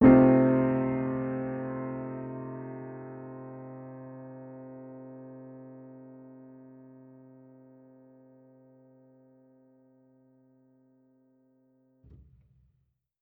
Index of /musicradar/jazz-keys-samples/Chord Hits/Acoustic Piano 2
JK_AcPiano2_Chord-Cm13.wav